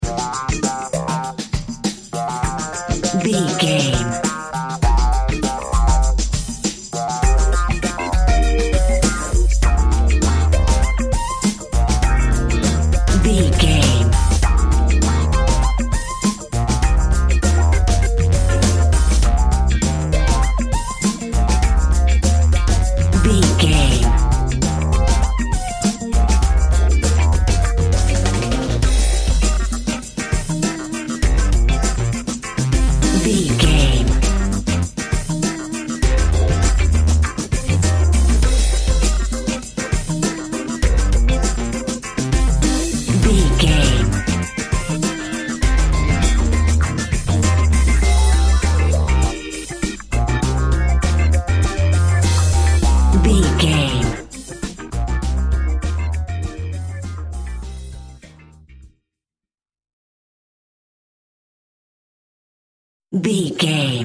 Aeolian/Minor
electronic
techno
synths
jazz drums
jazz bass
jazz guitar
jazz piano